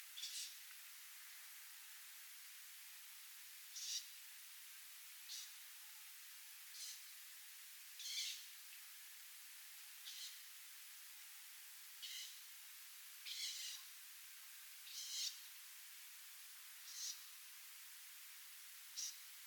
A nyest hangja:
Bár a nyest kutyaalkatúak alrendjébe sorolható, a hangja, és egyébként mozgása is jobban emlékeztet a macskára. Mint ragadozó állat a nyest alapvetően halk, de elnyúló macskaszerű visításával fel tudja magára hívni a figyelmet. De a nyest morgó hangja már előbb emlékeztet minket a kutyákra.